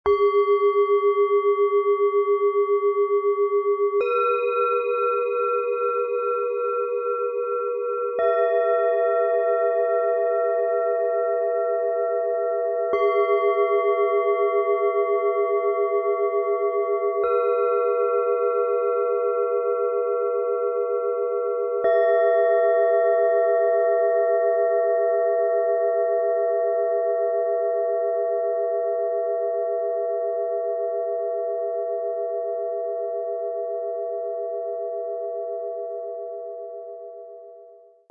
Freude der Delfine – Harmonie, Leichtigkeit, inneres Lächeln - Set aus 3 Klangschalen, Ø 10,3 - 13,5 cm, 1,27 kg
Dieses Set bringt ein Gefühl von sprudelnder Lebensfreude, innerem Ankommen und lichtvoller Harmonie. Die Klänge laden ein zum Loslassen, Auftauchen und Freuen – wie Delfine im Sonnenlicht.
Die hellste im Set bringt ein klares, strahlendes Klangbild. Sie springt freudig in höhere Sphären und weckt kindliches Staunen.
Im Sound-Player - Jetzt reinhören können Sie den Original-Ton genau dieser Schalen aus dem Set „Freude der Delfine“ anhören. Lassen Sie sich von den lichtvollen, freudigen Klängen inspirieren.
Der gratis Klöppel lässt die Schalen dieses Sets weich und harmonisch erklingen.
Bengalen Schale, Matt, 13,5 cm Durchmesser, 6,8 cm Höhe
MaterialBronze